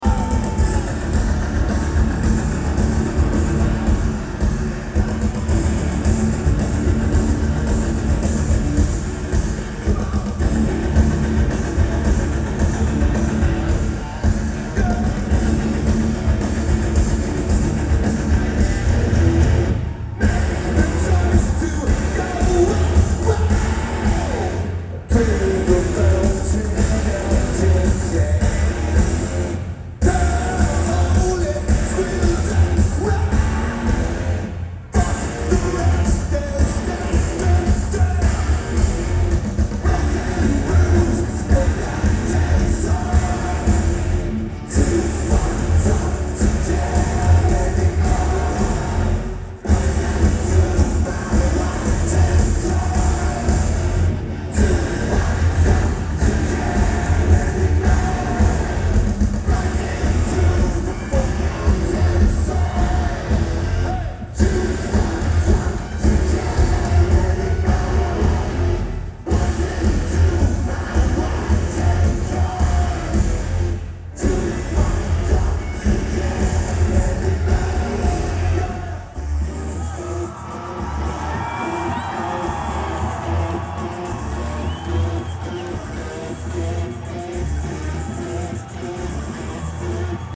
Verizon Wireless Amphitheater